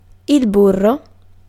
Ääntäminen
Ääntäminen RP : IPA : /ˈbʌ.tə/ UK : IPA : [ˈbʌt.ə] US : IPA : [ˈbʌɾ.ə] Tuntematon aksentti: IPA : /ˈbʌ.təɹ/ US : IPA : /ˈbʌ.tɚ/ IPA : [ˈbʌɾɚ] Northern and Midland England, Wales, Scotland: IPA : /ˈbʊ.tə/